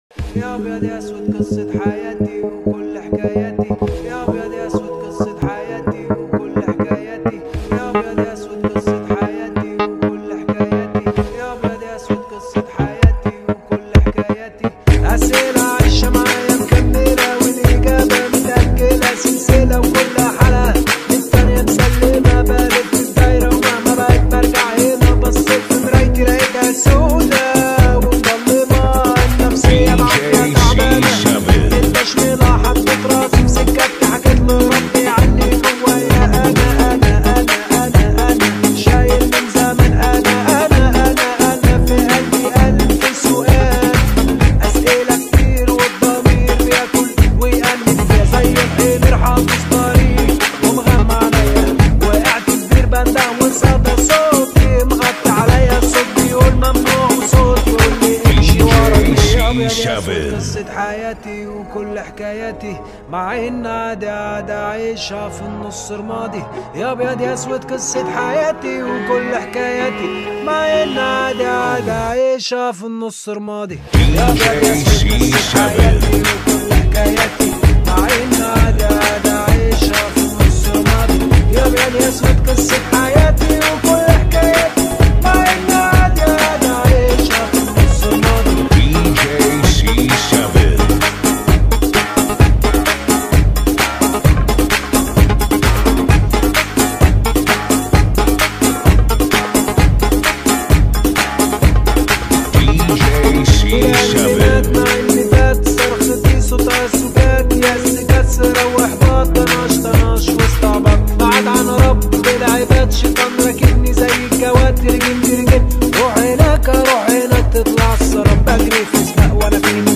بیس دار عربی
ریمیکس آهنگ عربی